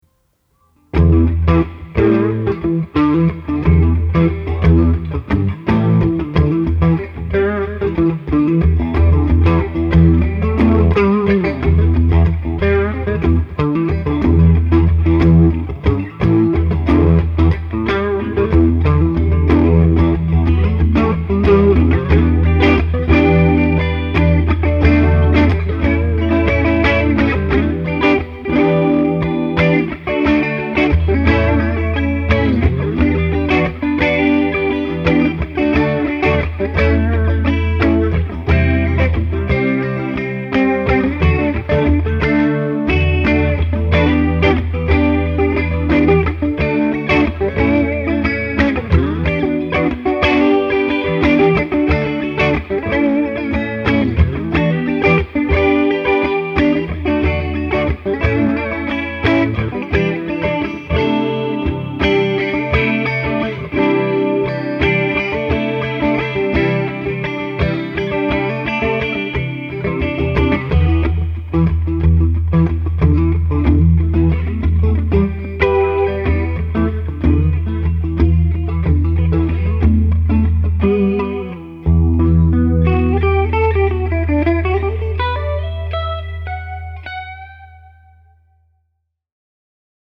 Liquid/Clean
In the next clip, I do sort of a funky rhythm with a syncopated bass line.
What I get is a real liquid tone:
corona_liquid.mp3